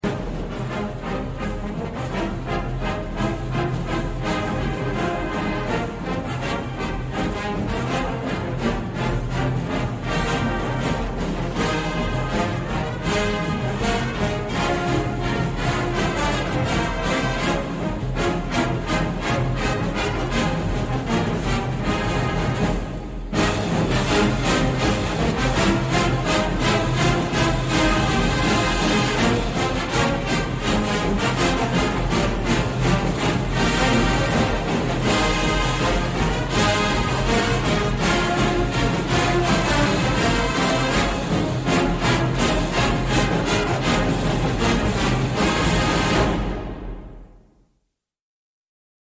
Wisconsin_anthem.mp3